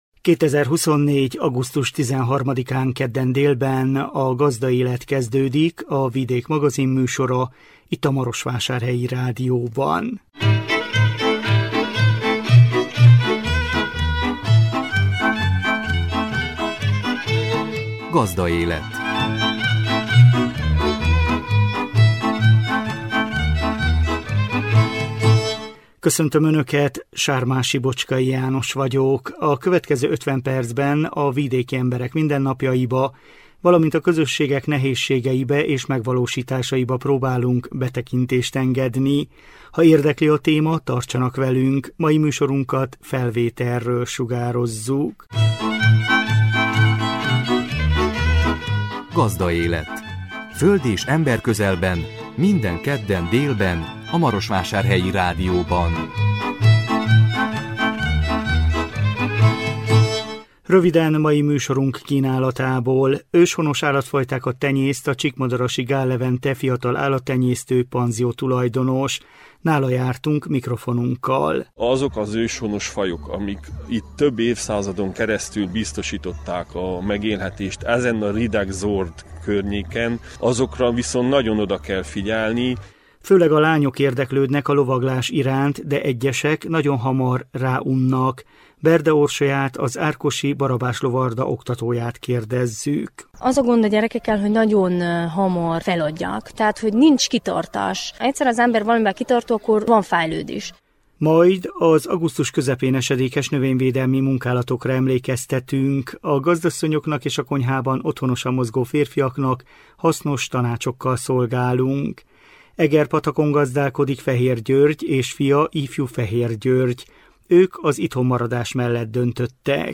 Nála jártunk mikrofonunkkal. Főleg a lányok érdeklődnek a lovaglás iránt, de egyesek nagyon hamar ráunnak.
Vele készült interjúnk.